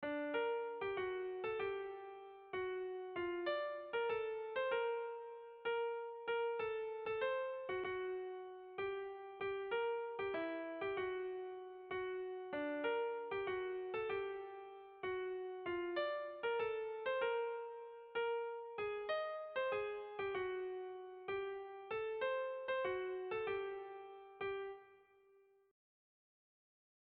Sentimenduzkoa
Zortziko txikia (hg) / Lau puntuko txikia (ip)
ABAD